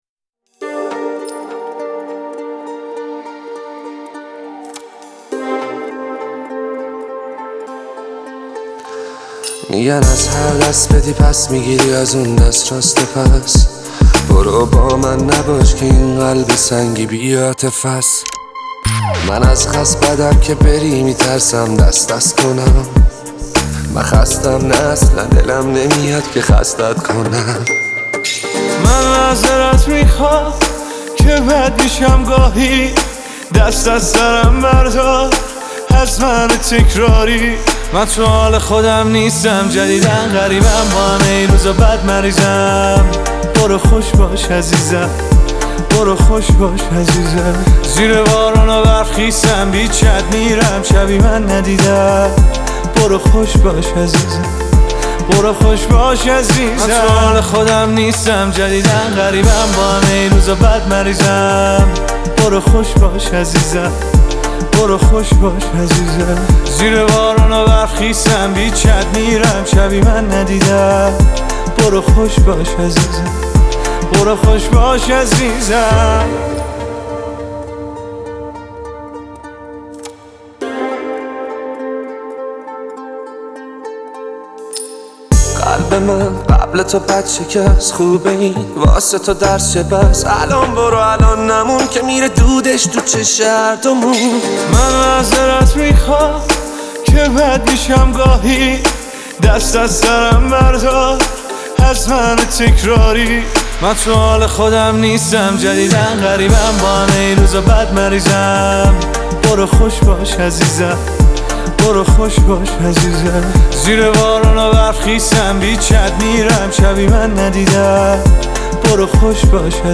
تک اهنگ ایرانی
غمگین